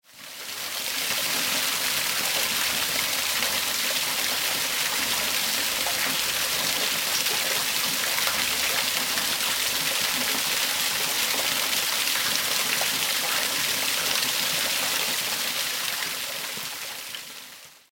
AGUA CATARATAS WATERFALL SMALL
Ambient sound effects
Agua_cataratas_Waterfall_Small.mp3